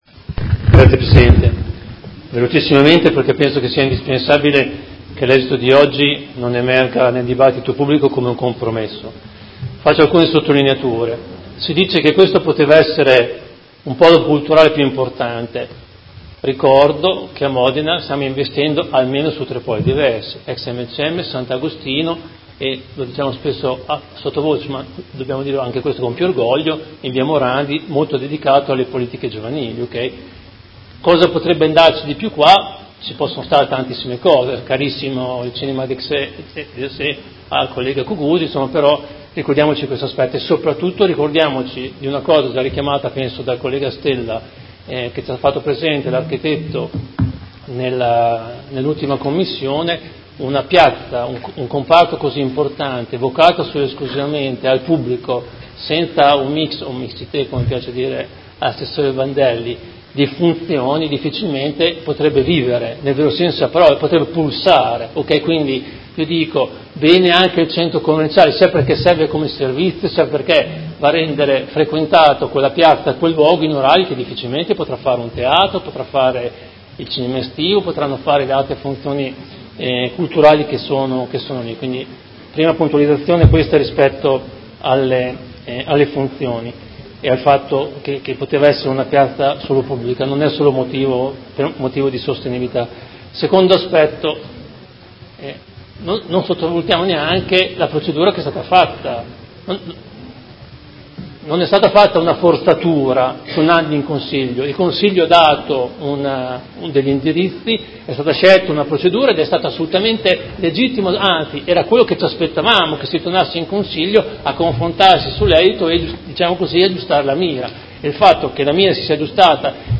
Seduta del 04/04/2019 Dichiarazione di voto.